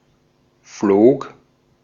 Ääntäminen
Ääntäminen Tuntematon aksentti: IPA: /floːk/ Haettu sana löytyi näillä lähdekielillä: saksa Käännöksiä ei löytynyt valitulle kohdekielelle. Flog on sanan fliegen imperfekti.